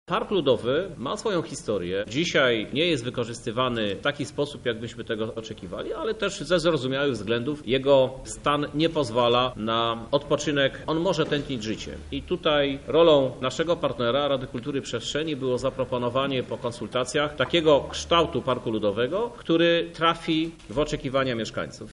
O czekających park zmianach mówi prezydent Lublina Krzysztof Żuk.